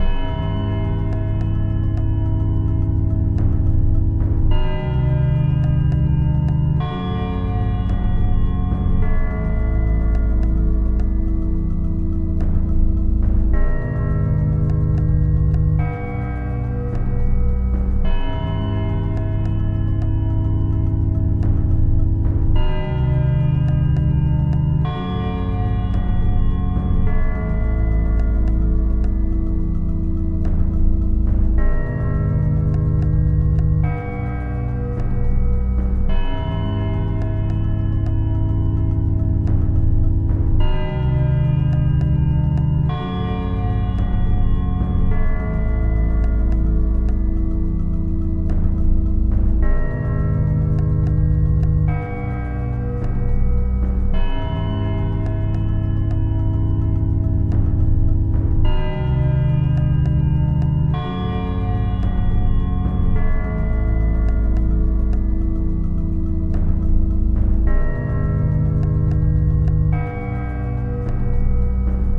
Speed 70%